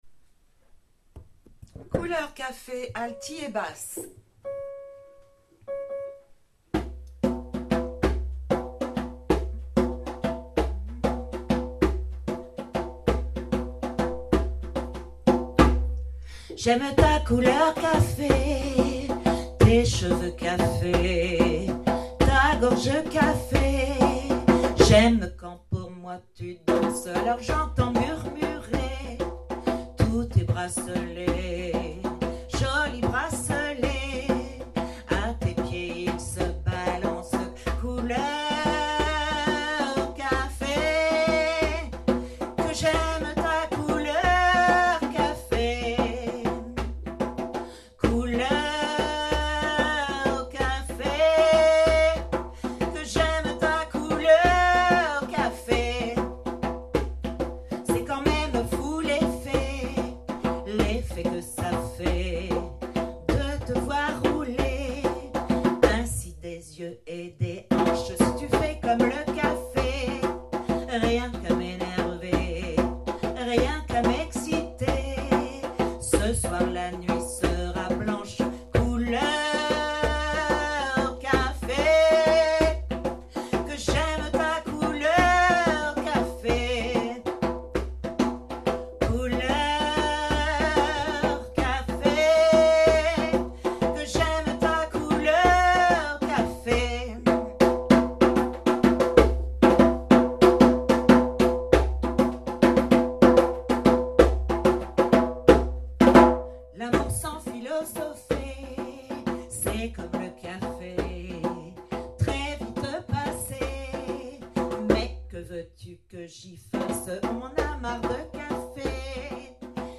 Couleur C Alti Basses percu